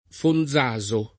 [ fon z#@ o ]